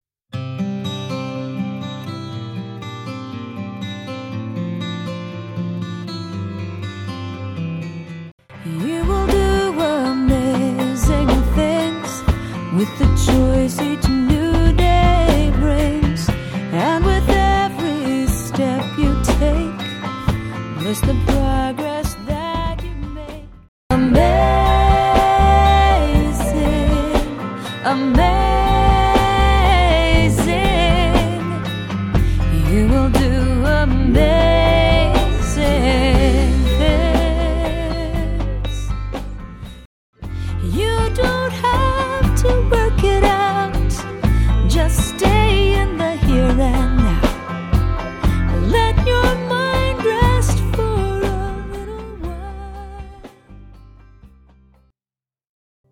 Contemplative or Free and Easy Solo with Optional SATB